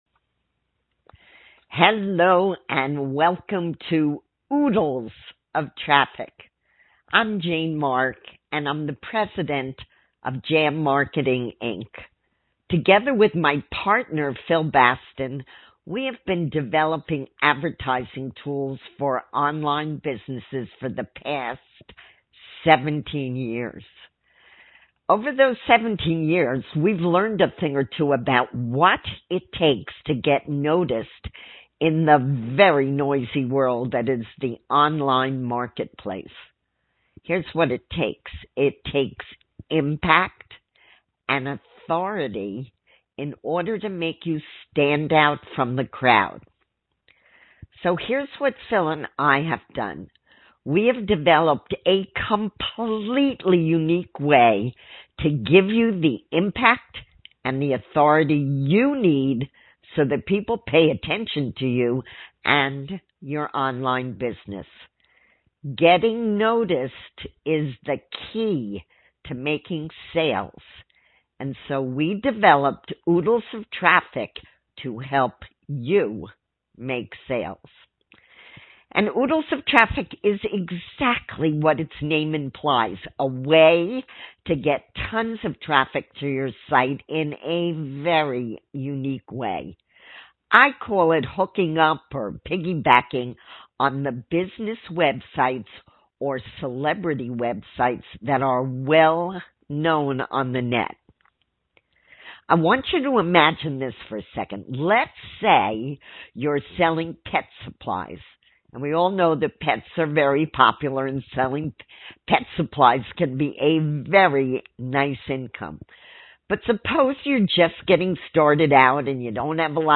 short audio presentation